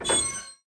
train-brake-screech-1.ogg